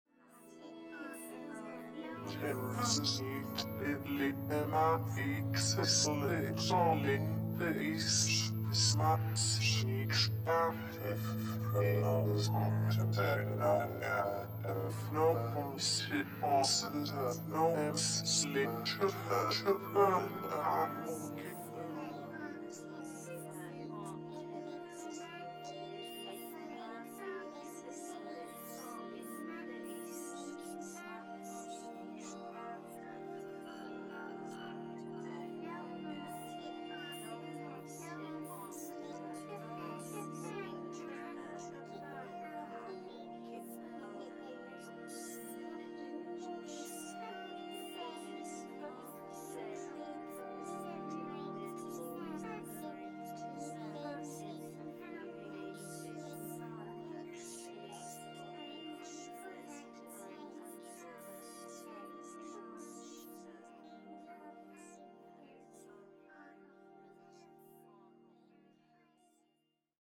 is a piece for synthesized voices and ambient electronics